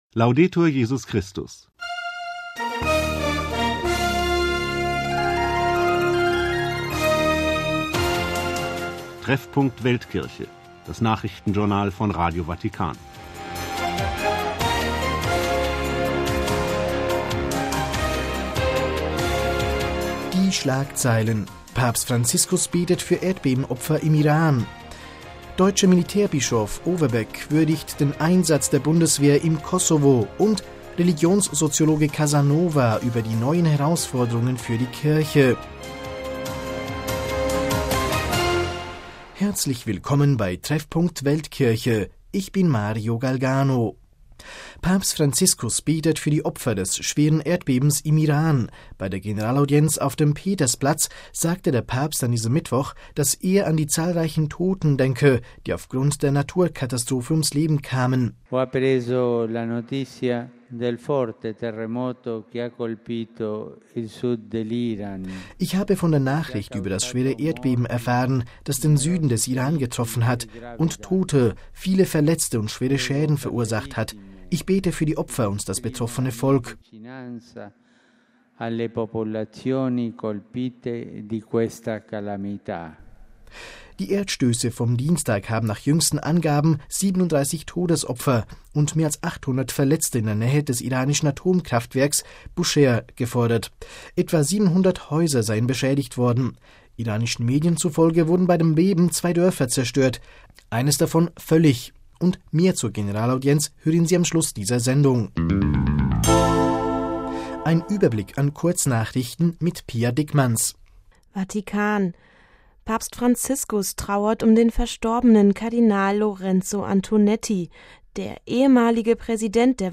Die Nachrichtensendung vom 10. April 2013